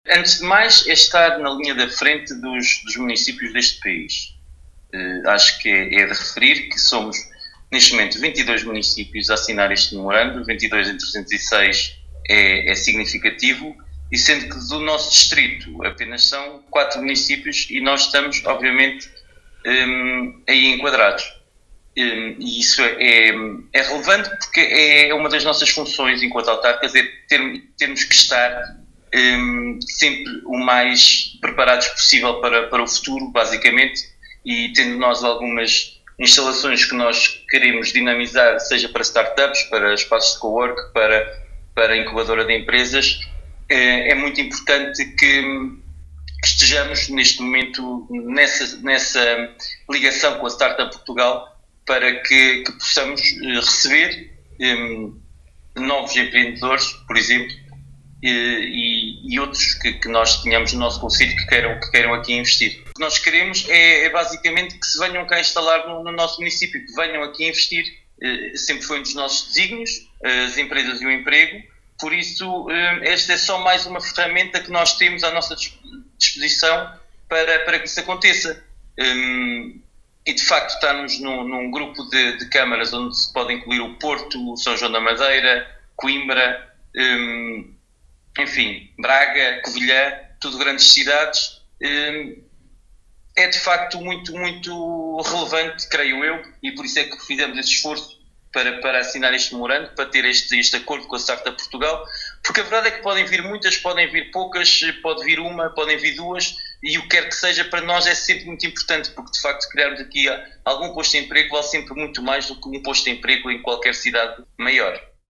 Paulo Marques, Presidente do Município de Vila Nova de Paiva, em declarações à Alive FM, fala da importância desta adesão no sentido de atrair mais investimento e empregos para o seu concelho.
Paulo-Marques-Startups.mp3